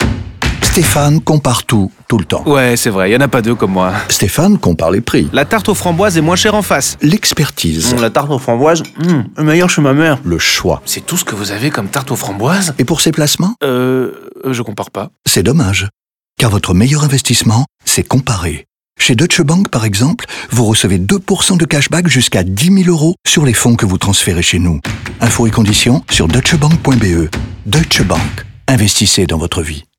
N’oublions pas la radio, mettant en scène Stéphane, un homme qui compare tout, tout le temps, sauf pour ses investissements, bien évidemment.
DeutscheBank-LeComparateur-Radio-FR-30s-Generique-051217.mp3